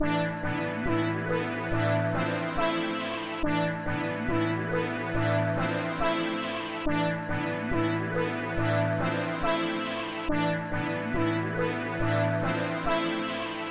描述：喇叭声，喇叭声，喇叭声这给了我A$AP Mob的感觉。
标签： 140 bpm Trap Loops Woodwind Loops 2.31 MB wav Key : Unknown
声道立体声